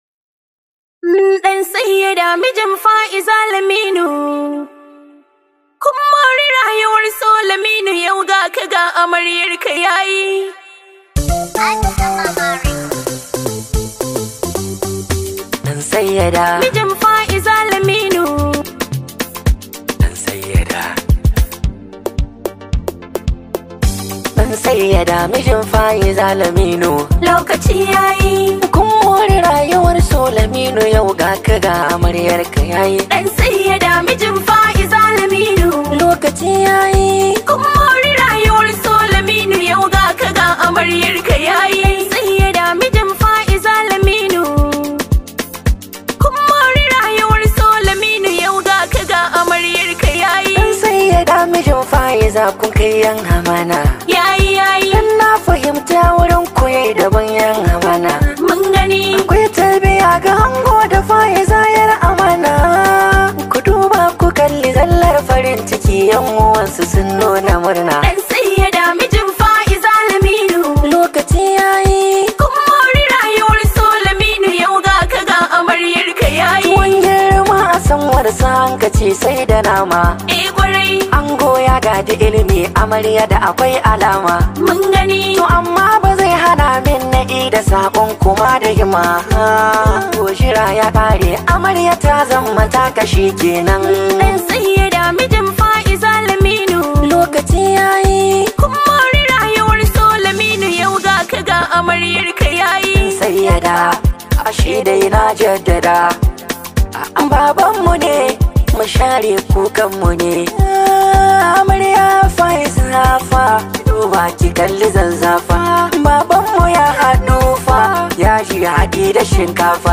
Hausa Singer